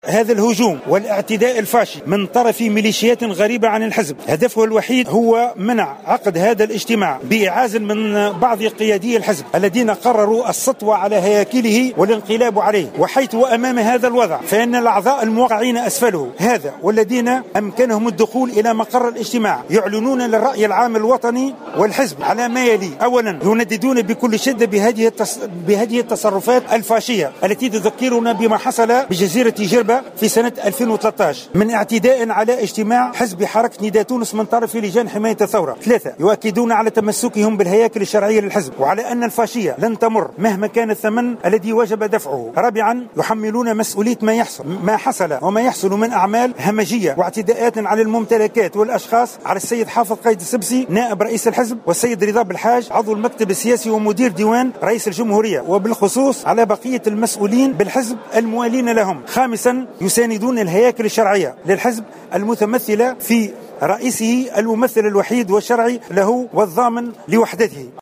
البيان يتلوه القيادي عبد المجيد الصحراوي